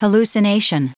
Prononciation du mot hallucination en anglais (fichier audio)